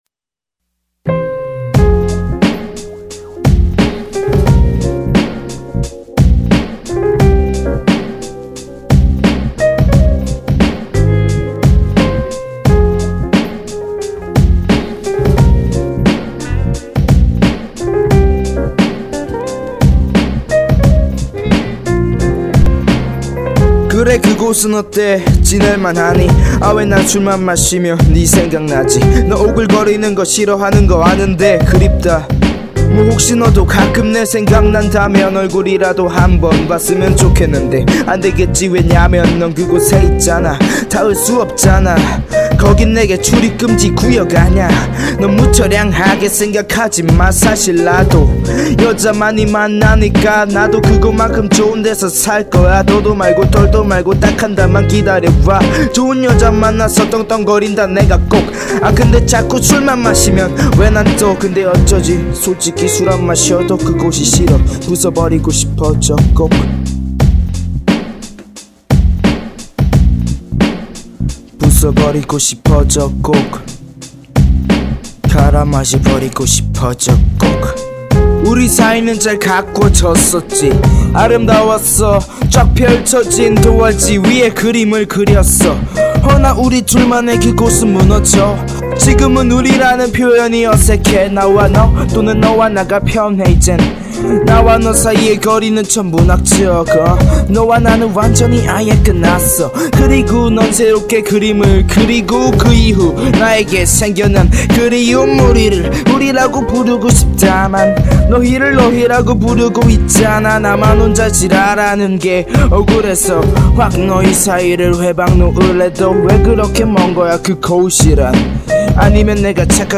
• [REMIX.]